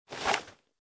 wpn_pistol10mm_equip.wav